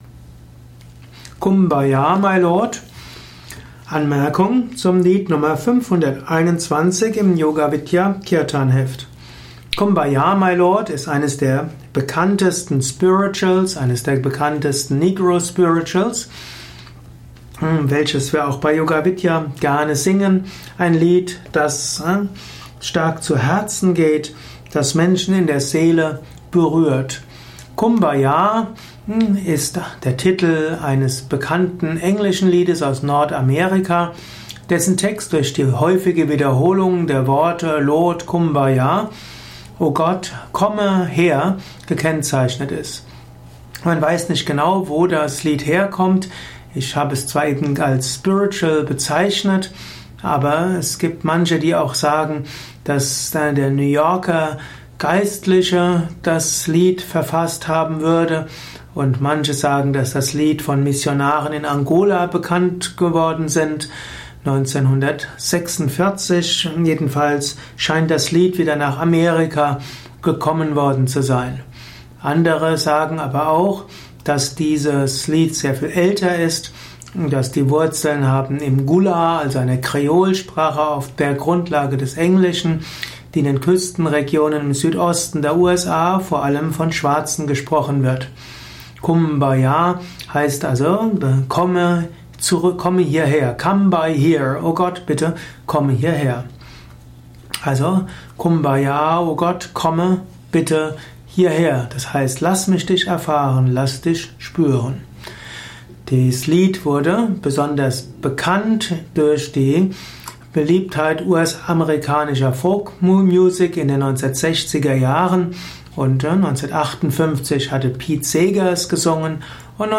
Ein Kurzvortrag mit Übersetzung und Erklärungen zum Kumbaya My Lord